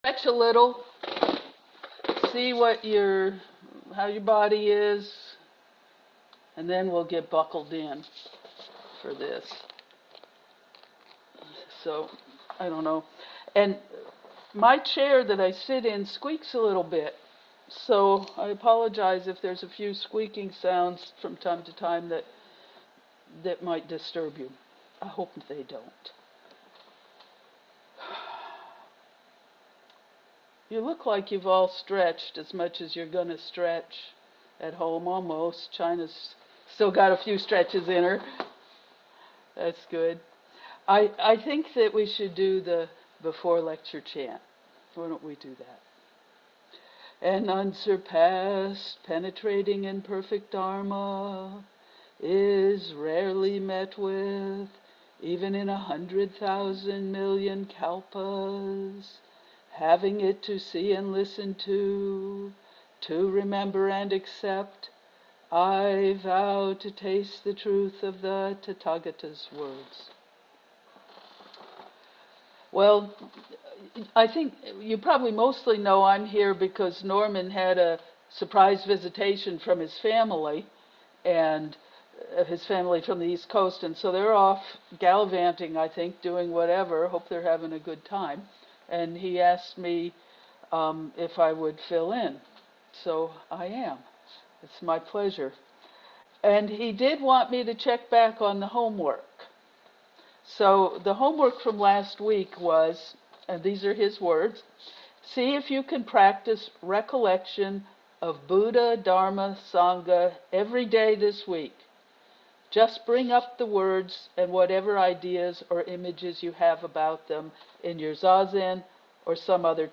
gives the fourteenth talk of the Dhammapada series to the Everyday Zen dharma seminar. The Dhammapada or “Path of Dharma” is a collection of verses in the Pali Canon that encapsulates the Buddha’s teachings on ethics, meditation and wisdom and emphasizes practical guidance for living a virtuous life.